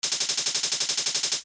Sixteenth Notes
First, set your DAW to about 175 bpm and paint 16th notes.
It sounds much like a machine gun, which might get tiring to listen to in a whole song.